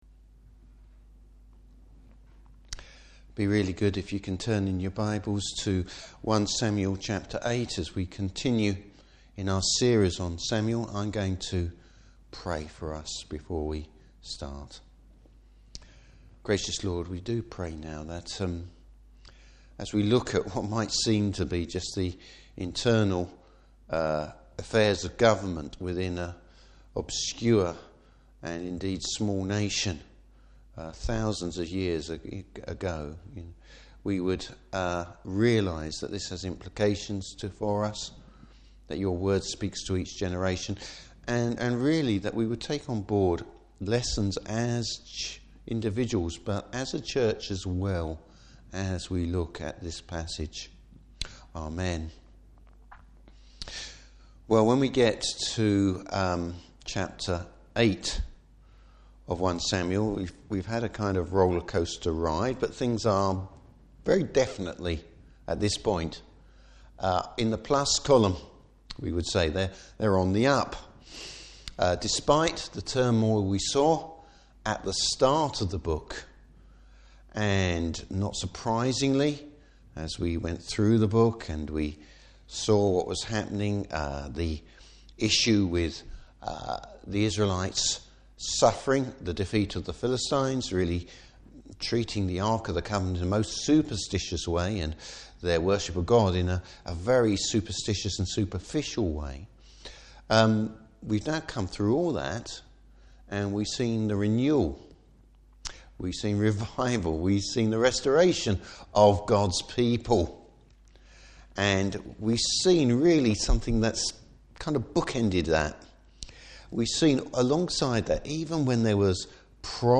Service Type: Evening Service Tell tale signs that the Lord is being rejected!